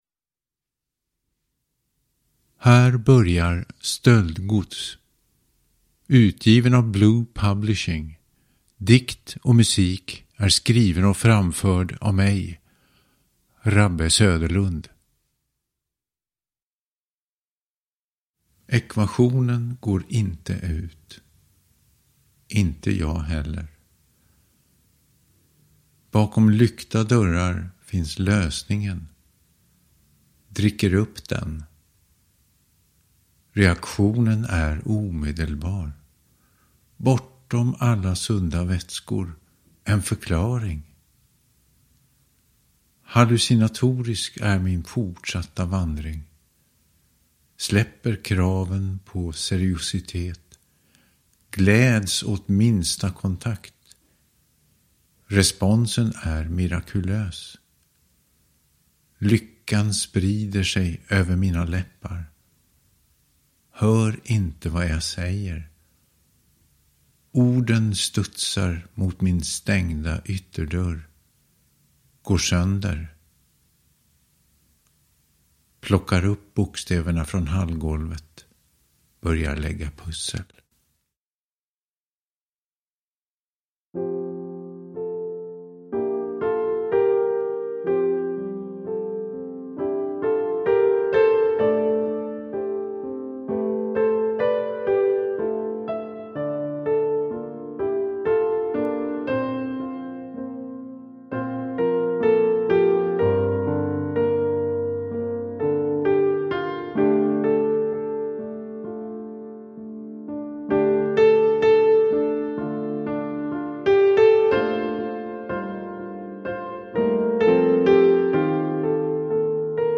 Stöldgods – Ljudbok